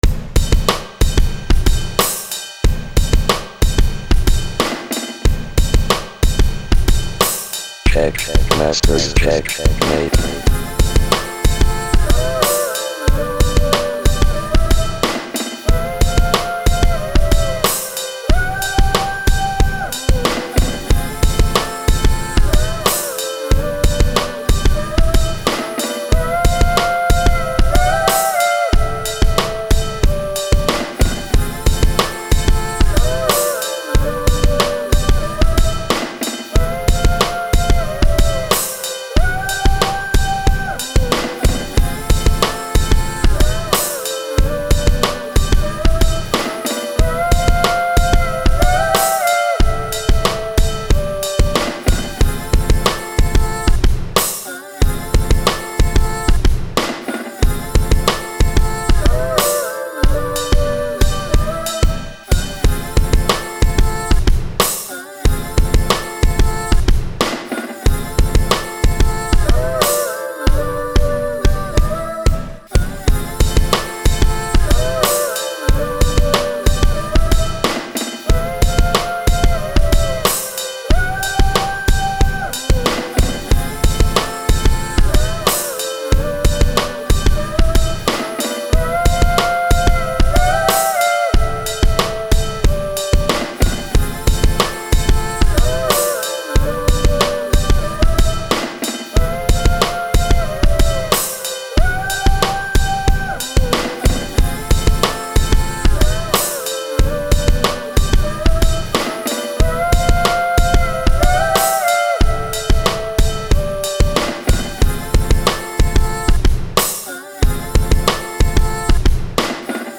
드럼은 나름 신경을 많이 써서 소리를 조절한 비트입니다.
스네어도 다르게 써보고 킥도 전부 피치조정을 해봤습니다.
이지하게 프리스타일하면서 살아가는 얘기 읊조리는 느낌으로 만들어봤습니다.